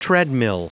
Prononciation du mot treadmill en anglais (fichier audio)
treadmill.wav